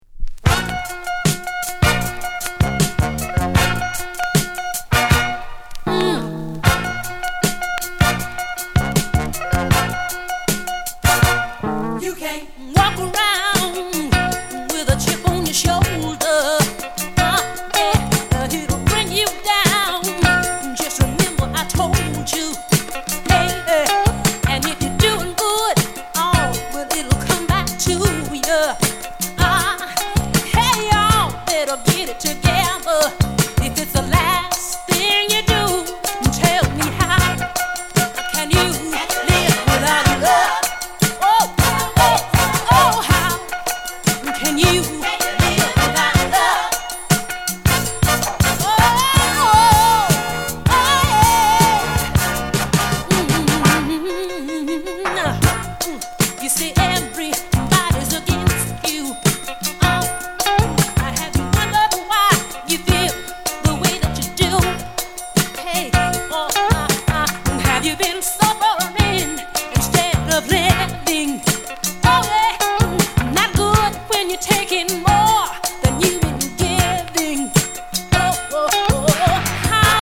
Genre: Rare Groove